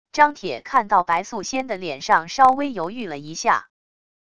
张铁看到白素仙的脸上稍微犹豫了一下wav音频生成系统WAV Audio Player